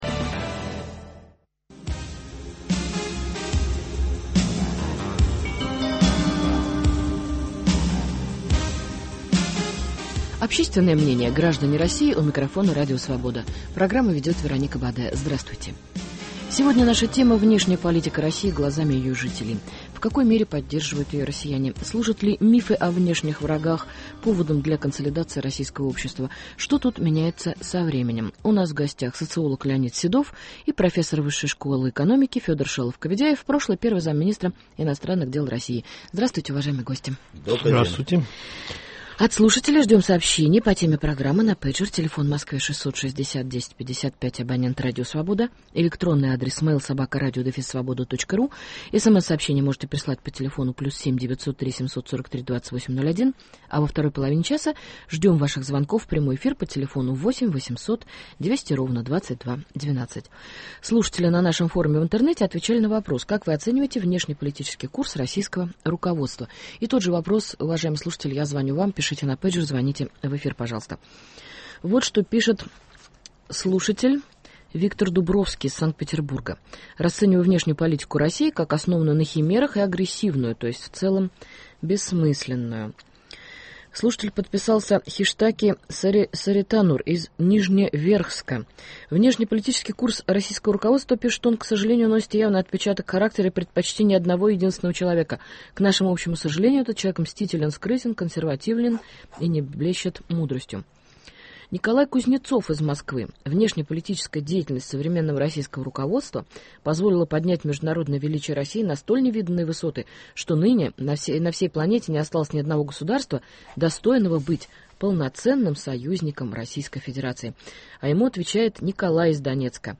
Внешняя политика России глазами ее жителей. Гости в студии Радио Свобода